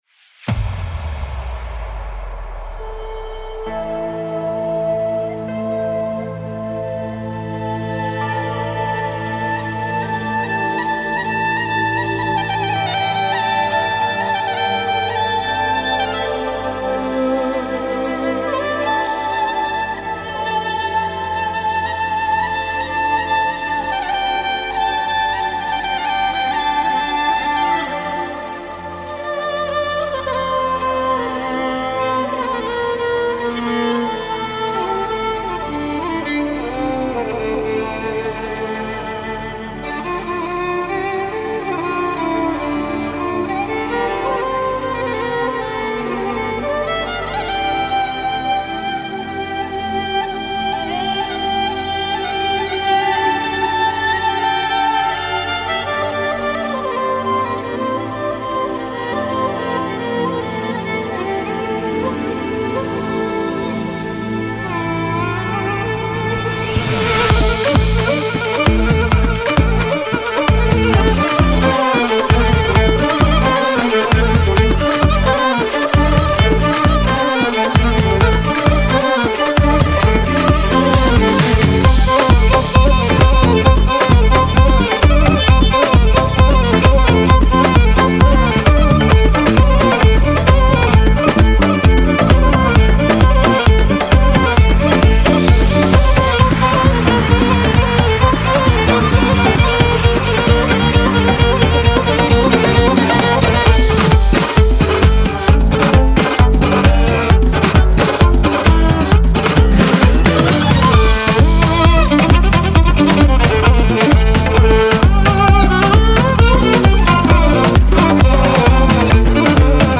آهنگ بی کلام غمگین ویولن